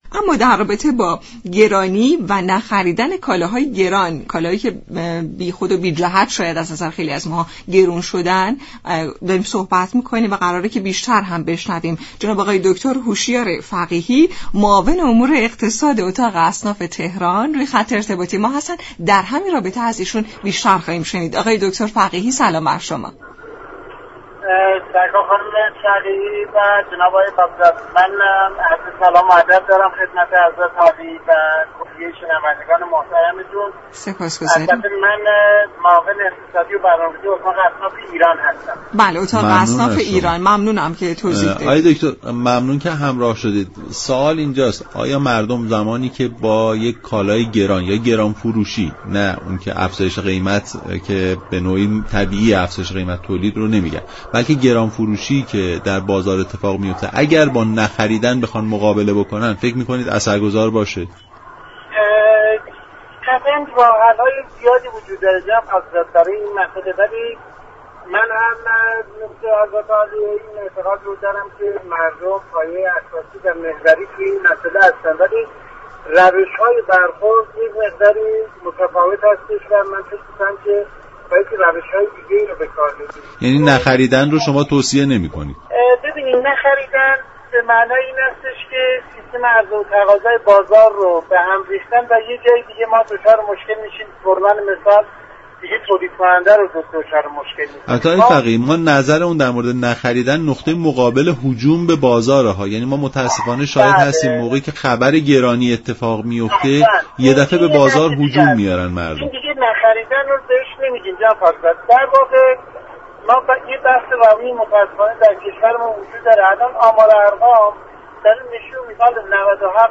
رویداد
برنامه نمودار شنبه تا چهارشنبه هر هفته ساعت 10:20 از رادیو ایران پخش می شود.